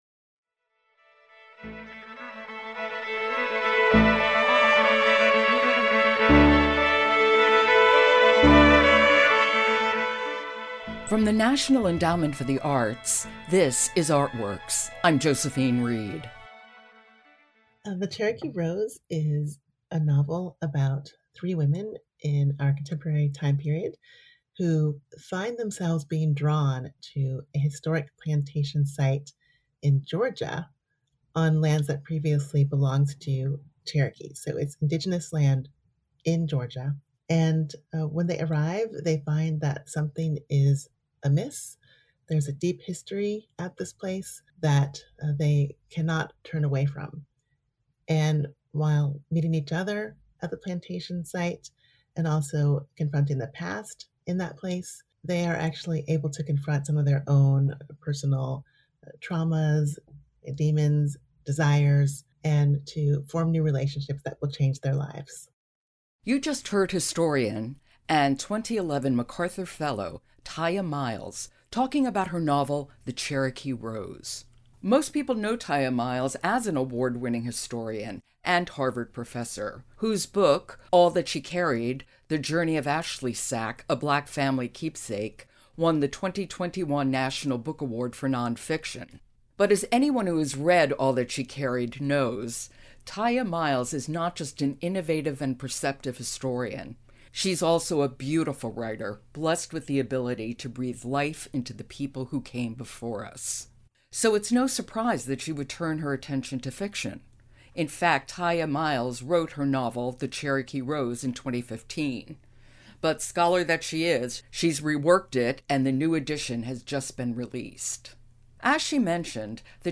2011 MacArthur Fellow, 2021 National Book Award winner, historian, and novelist Tiya Miles discusses how fiction and history meet in her novel.